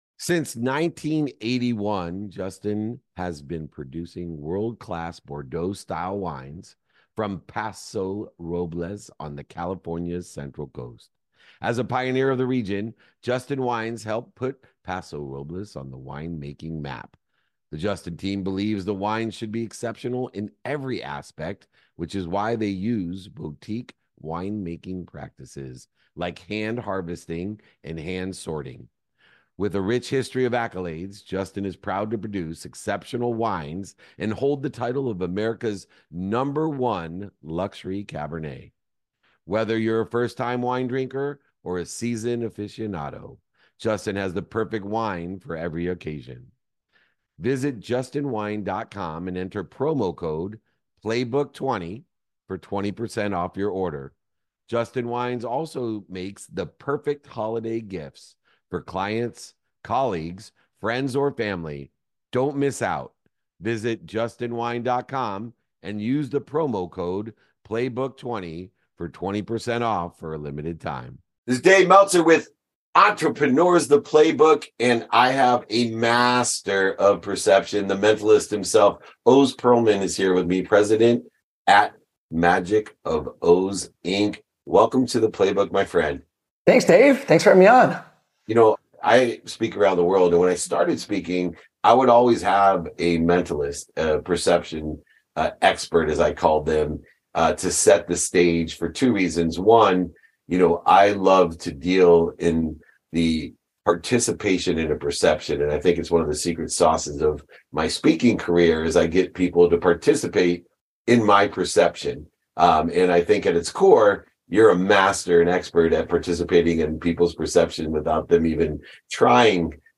From reading minds to reading rooms, this conversation shows how perception can be a powerful business tool.